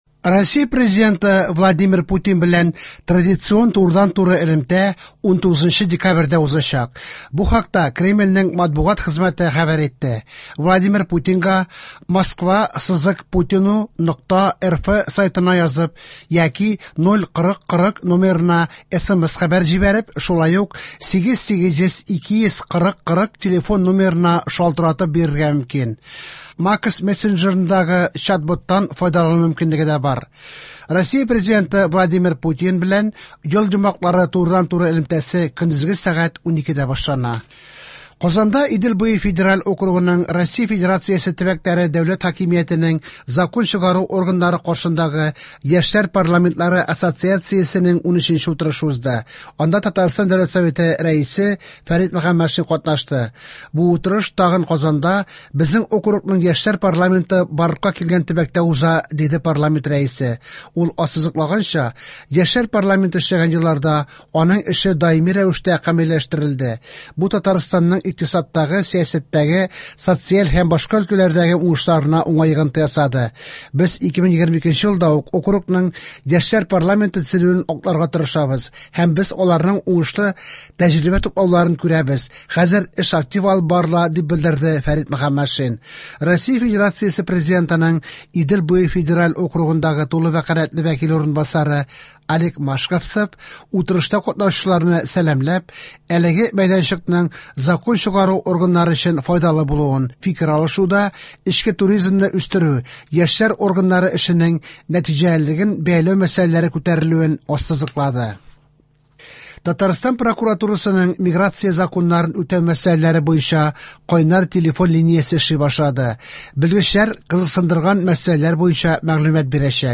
Вечерние выпуски